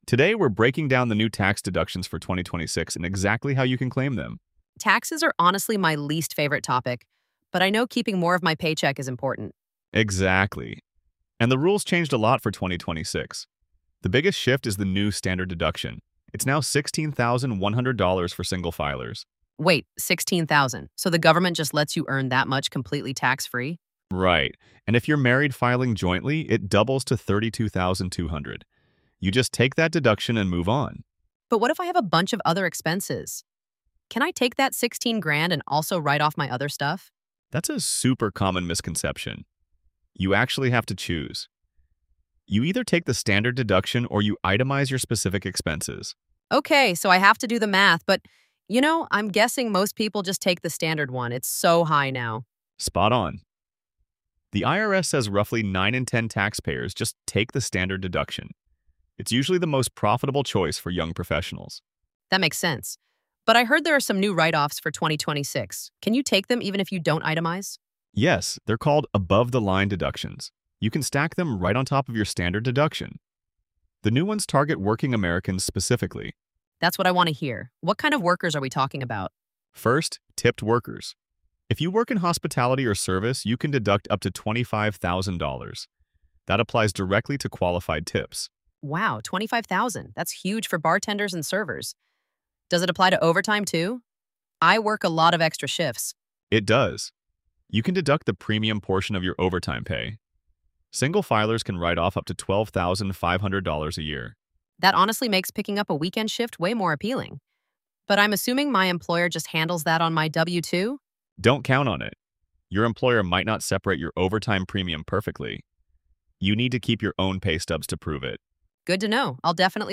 AI-generated audio · Voices by ElevenLabs